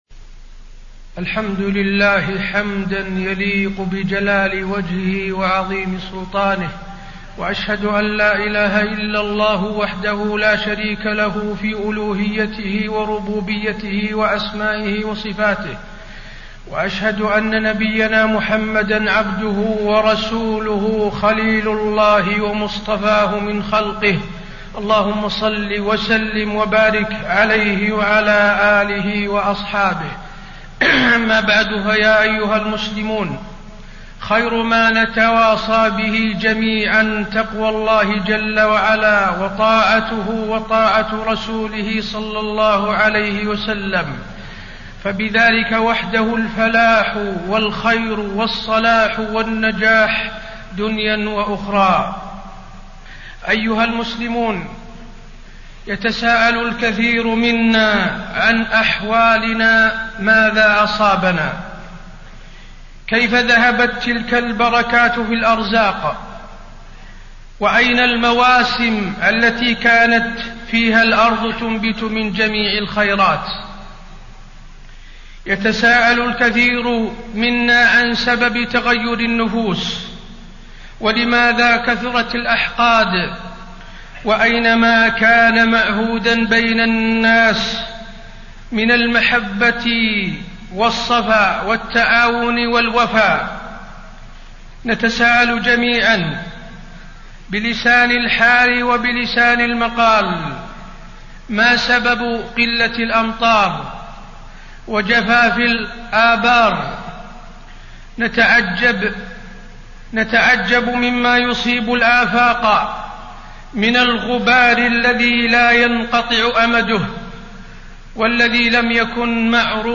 تاريخ النشر ٢ ربيع الثاني ١٤٣٣ هـ المكان: المسجد النبوي الشيخ: فضيلة الشيخ د. حسين بن عبدالعزيز آل الشيخ فضيلة الشيخ د. حسين بن عبدالعزيز آل الشيخ المصائب الدنيوية الداء والدواء